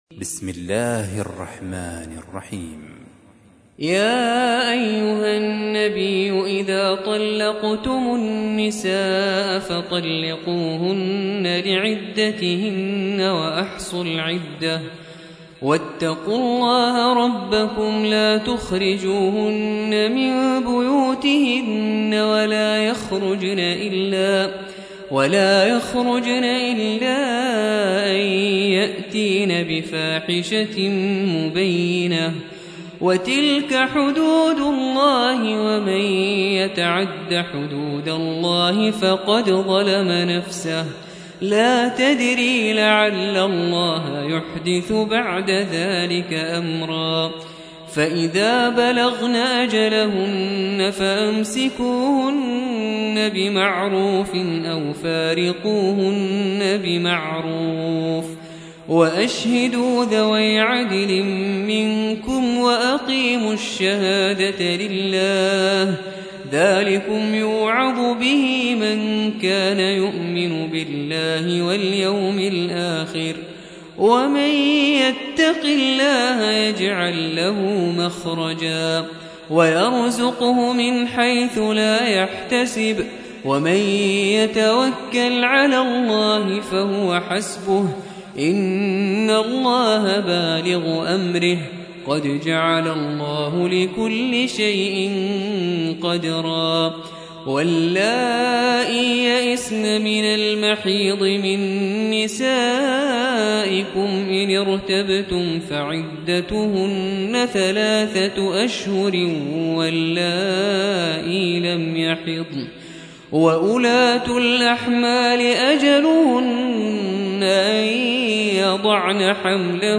تحميل : 65. سورة الطلاق / القارئ نبيل الرفاعي / القرآن الكريم / موقع يا حسين